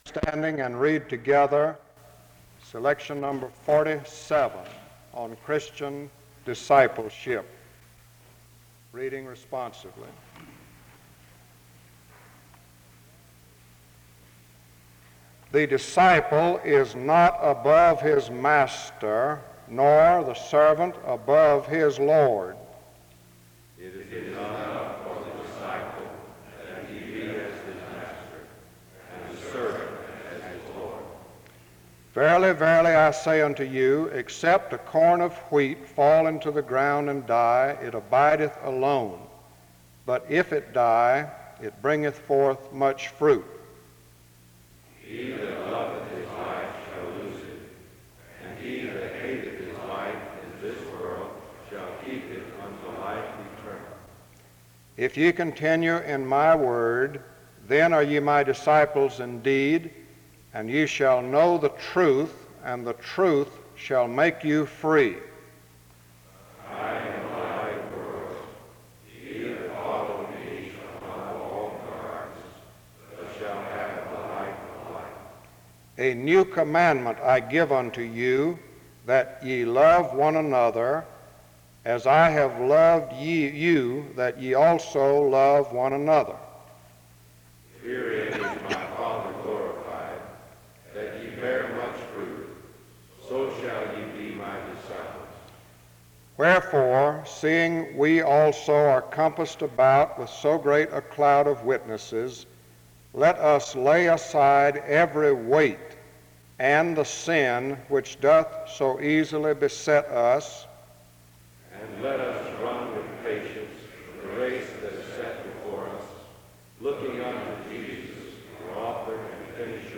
The service begins with a responsive reading from 0:00-2:02. Music plays from 2:05-4:05. A prayer is offered from 4:10-6:07. An introduction to the speaker is given from 6:11-7:33.